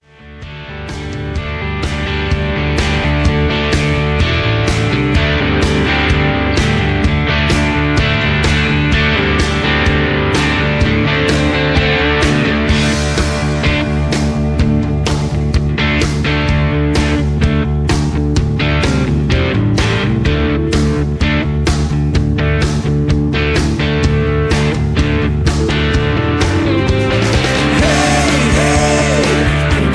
Tags: studio tracks , sound tracks , backing tracks , rock